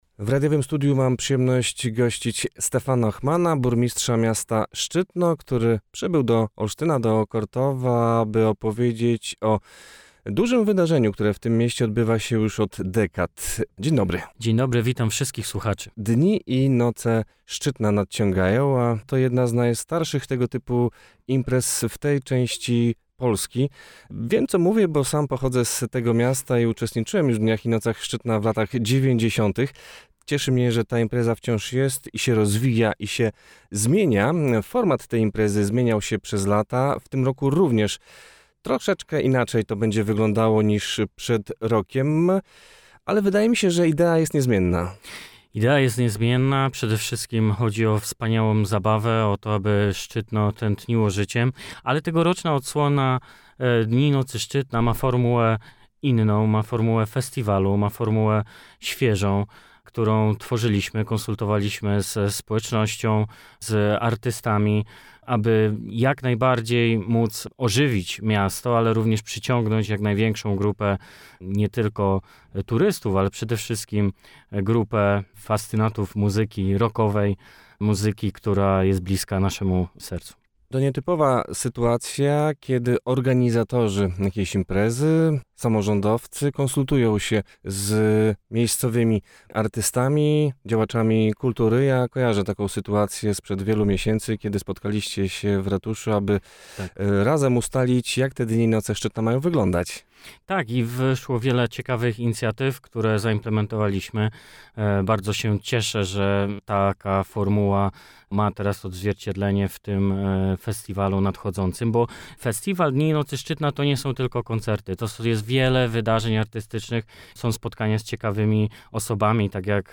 O szczegółach święta mazurskiego miasta opowiedział na naszej antenie.